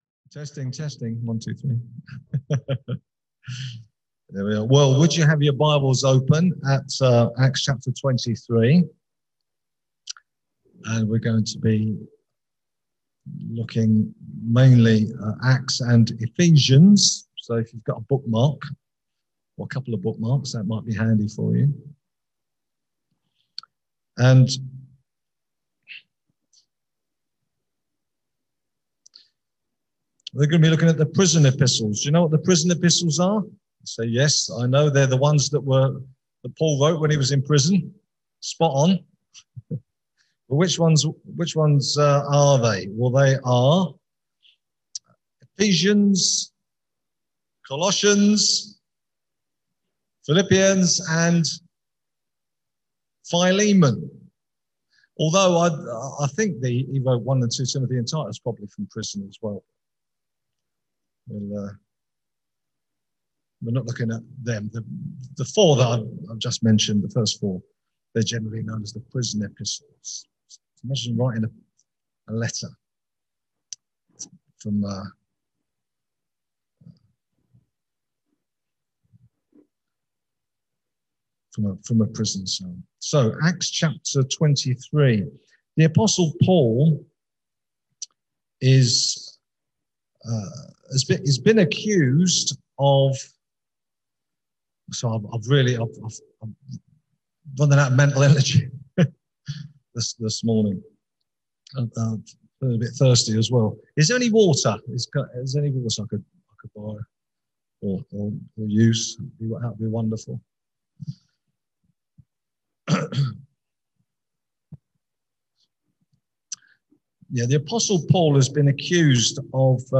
Below is the recording of the sermon for this week.